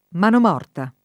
manom0rta] s. f. (giur. «proprietà immobiliare indisponibile»); pl. manimorte (raro manomorte) — meno com. mano morta [id.] — ma sempre in gf. divisa mano morta nel sign. fam. di «mano inerte», sia con allus. a molestie sessuali così mascherate, sia con un senso non cattivo: lei restava seduta con le mani morte nel grembo in attitudine di attesa [l$i reSt#va Sed2ta kon le m#ni m0rte nel gr$mbo in attit2dine di att%Sa] (Moretti)